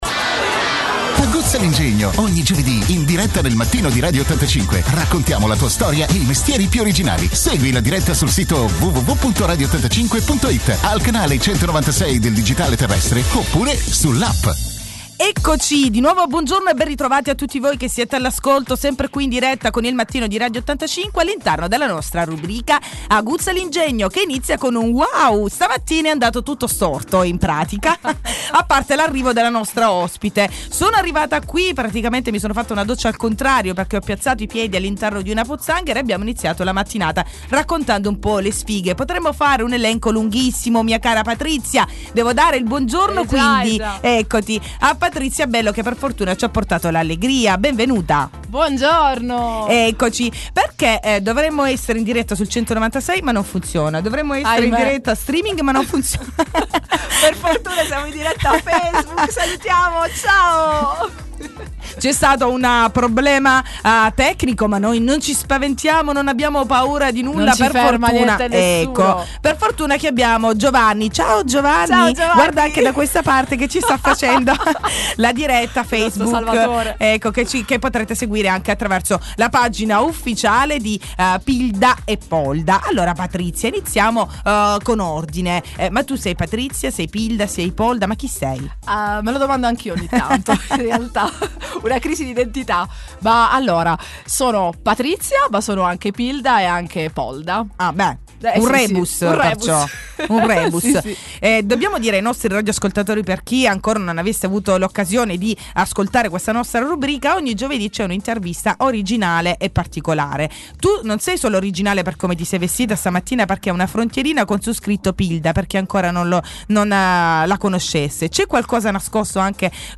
Clicca e riascolta l’intervista all’ ospite di questa settimana della rubrica Aguzza l’ingegno